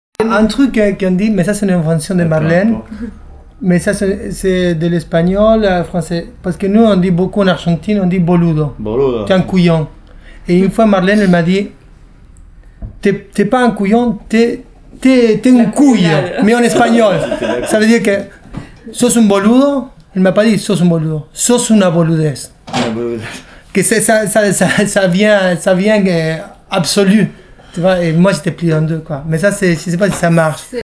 boludez_explication01.mp3